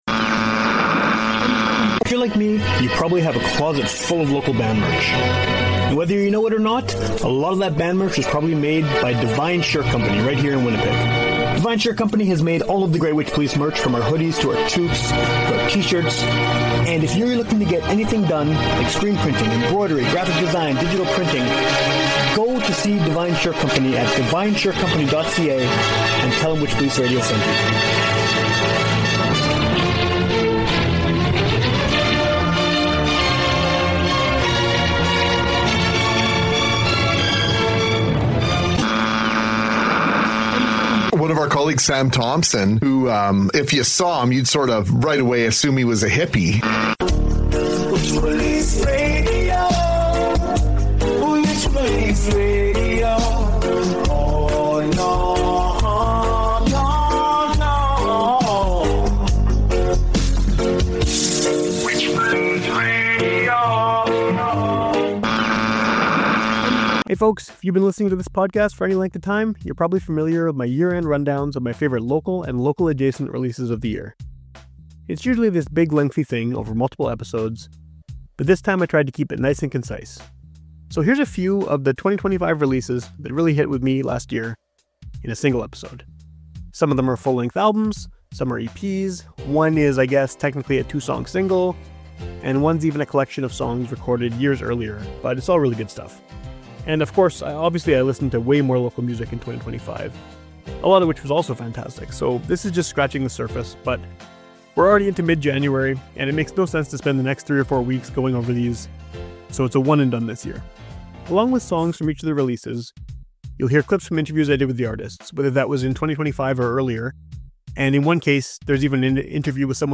Along with songs from each of the releases, you'll hear clips from interviews I did with the artists -- or relevant